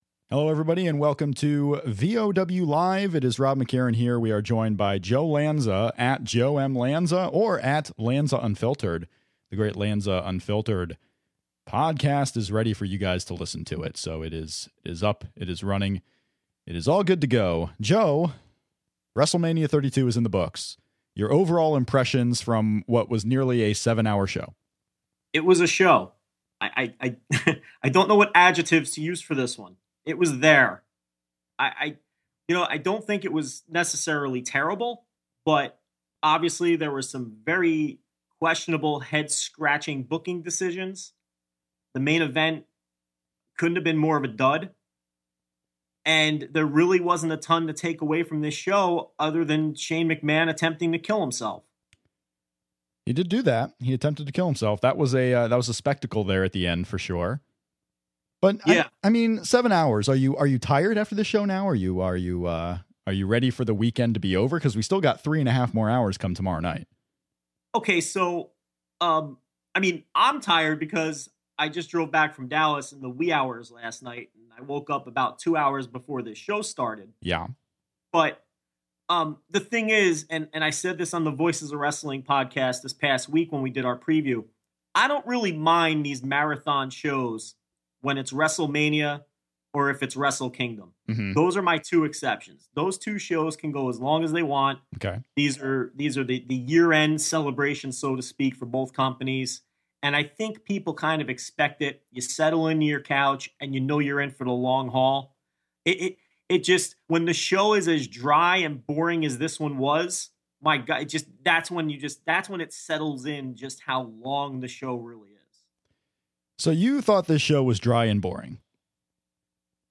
WWE WrestleMania 32 is in the books, and we're live with our reaction show at Voices of Wrestling.